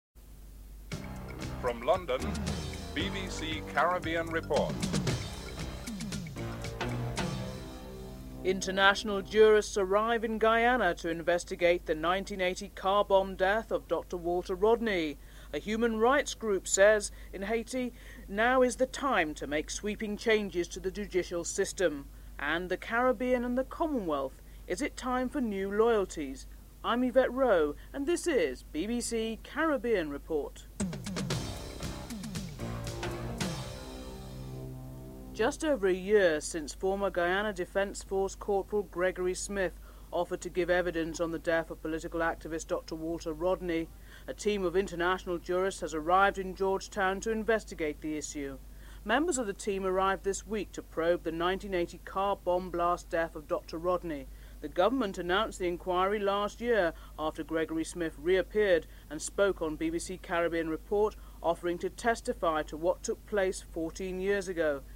Labour MP Peter Shore spoke at Westminster on this issue.